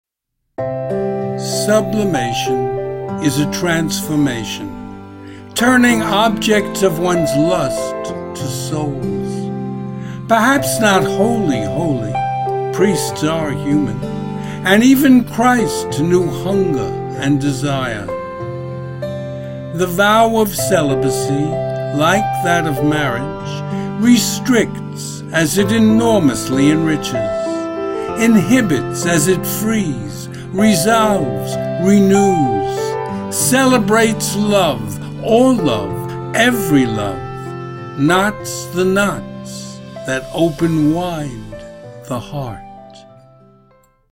Video and Audio Music:
Emotional Love Theme.